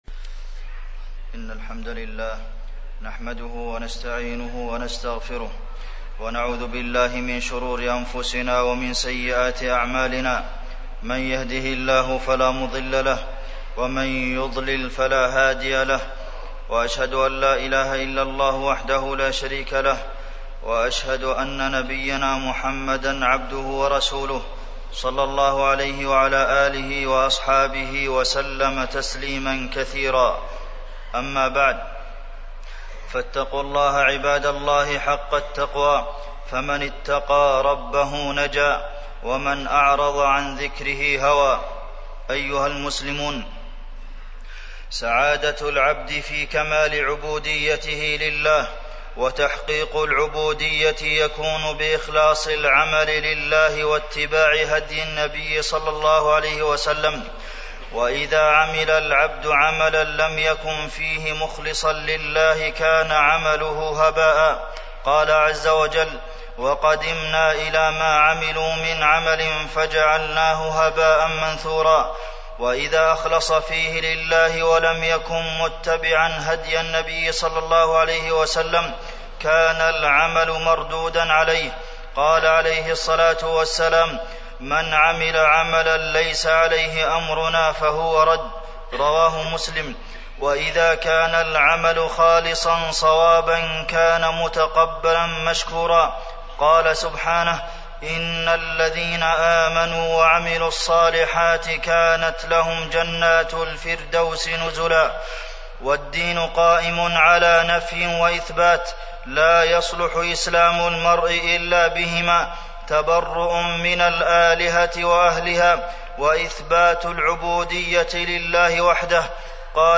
تاريخ النشر ٢٨ ذو القعدة ١٤٢٦ هـ المكان: المسجد النبوي الشيخ: فضيلة الشيخ د. عبدالمحسن بن محمد القاسم فضيلة الشيخ د. عبدالمحسن بن محمد القاسم الغلو في الأموات والصالحين وأثره في الدين